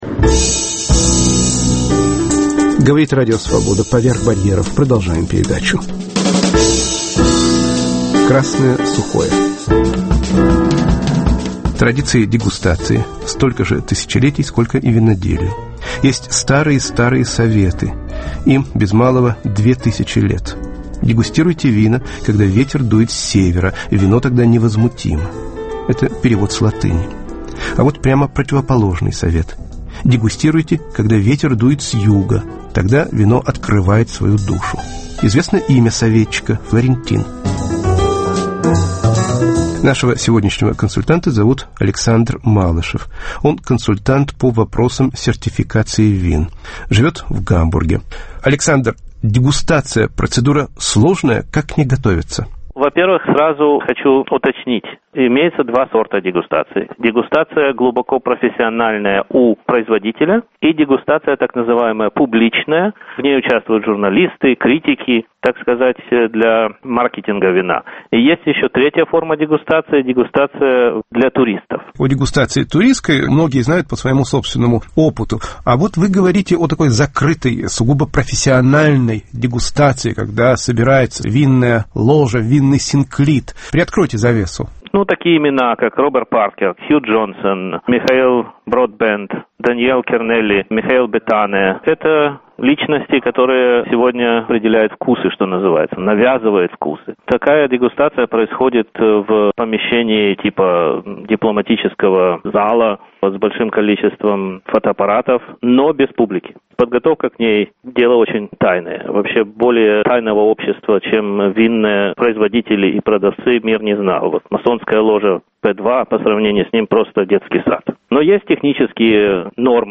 "Красное сухое" - беседа с экспертом по вопросам сертификации вин.